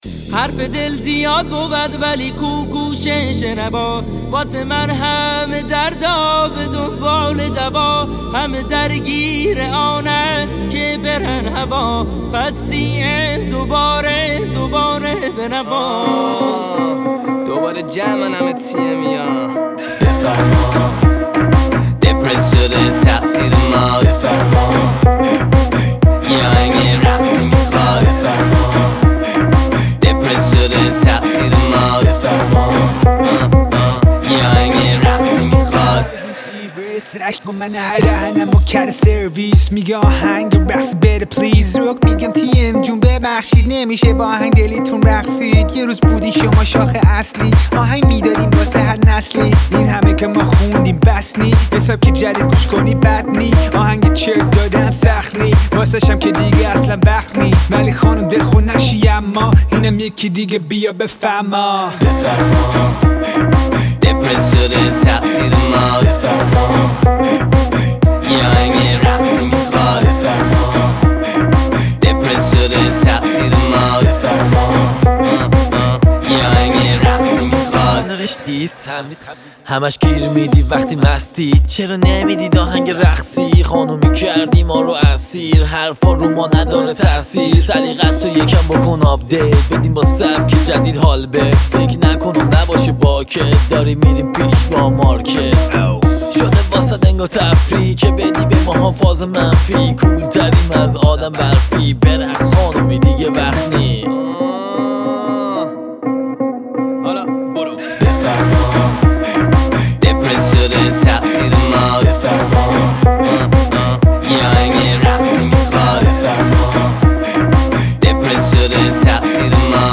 ژانر: رپ ، پاپ